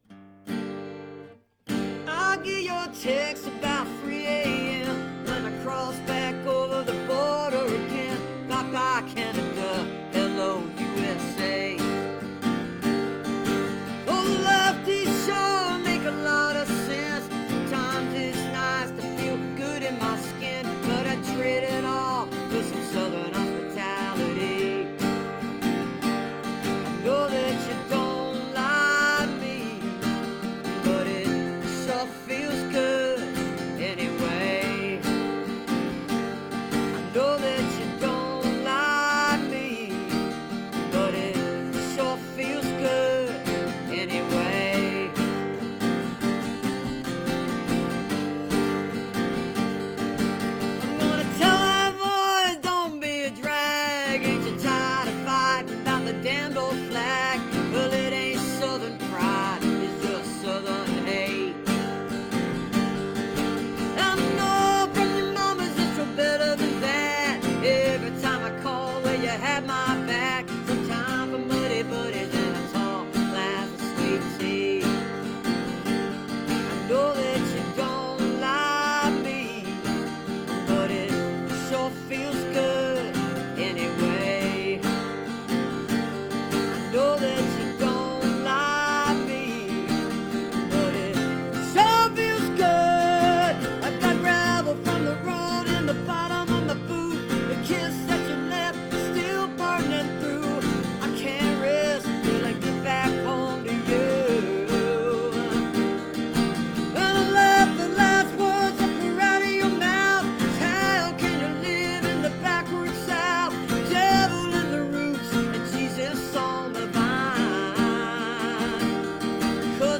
(captured from the facebook live video stream)